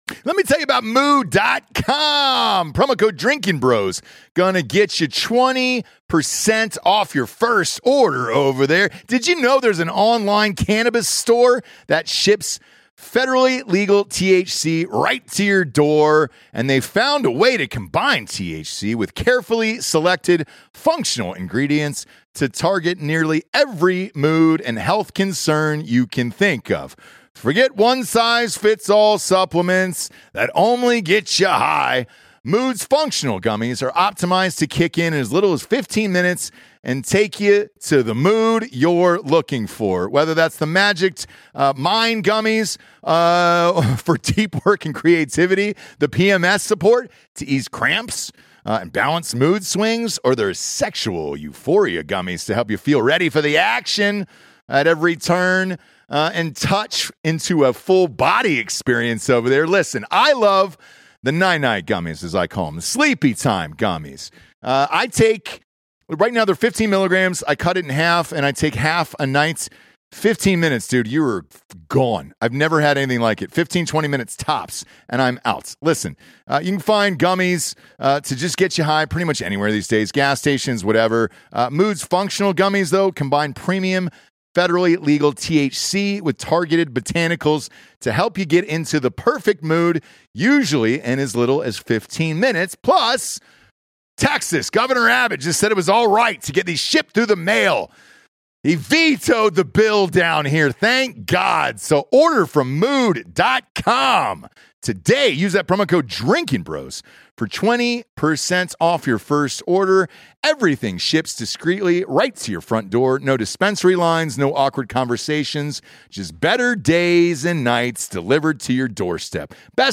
live in Las Vegas